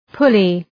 Προφορά
{‘pʋlı}